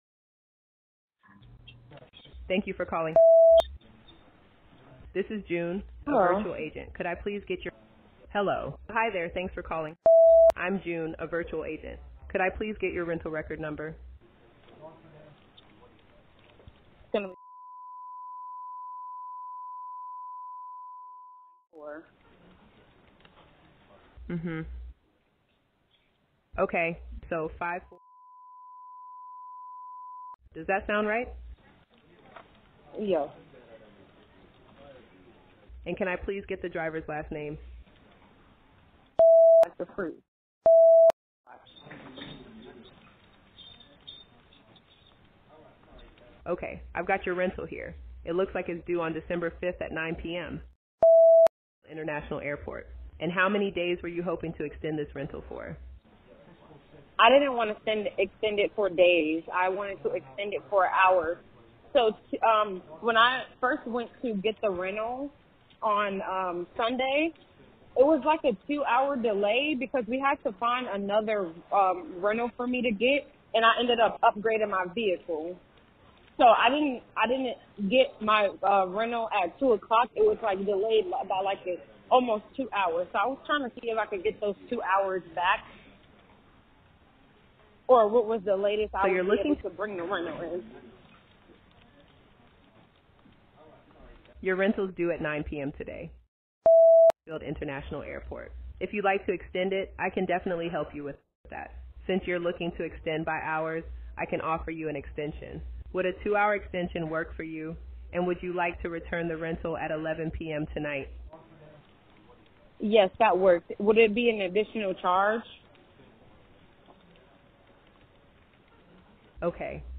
A Travel Phone Call handled by Our AI VOICE AGENT
A-Travel-Phone-Call-handled-by-Our-AI-online-audio-converter.com_.wav